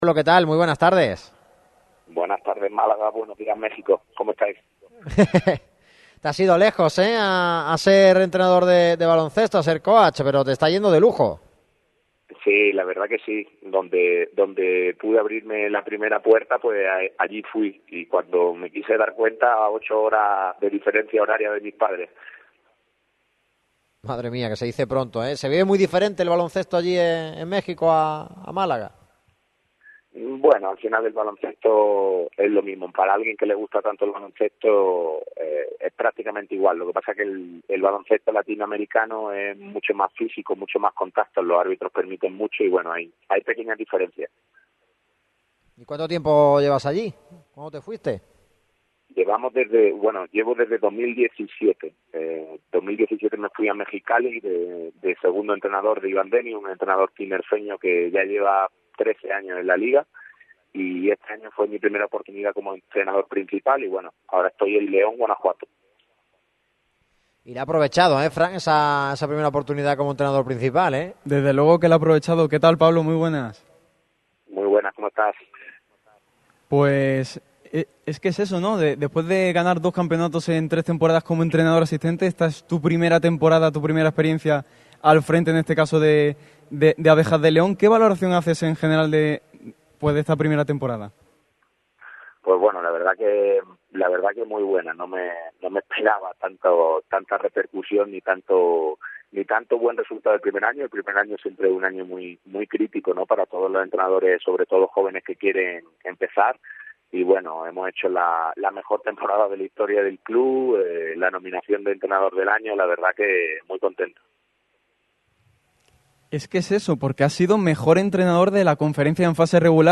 «Fui a donde pude abrirme la primera puerta», declaró en los micrófonos de Radio MARCA Málaga.